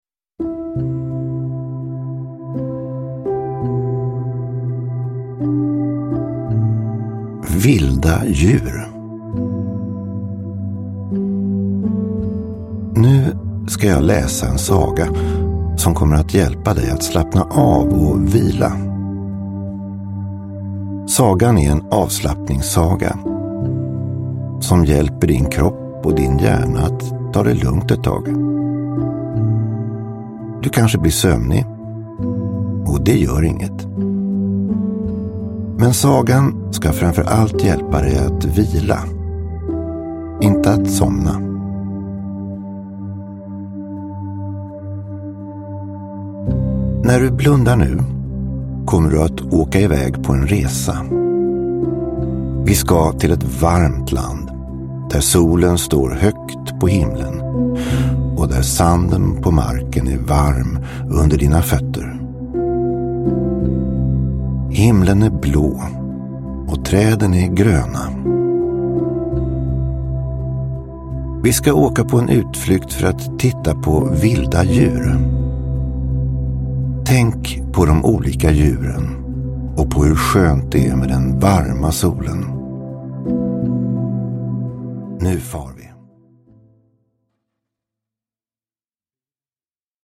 Avslappningssagor. Vilda djur – Ljudbok – Laddas ner
Lyssna på boken för att skapa en avkopplande vilostund med hjälp av lugna sagor inspirerade av mindfulness. För aktiva barn som behöver gå ner i varv och avslappningsövningar som ni kan göra hela familjen tillsammans.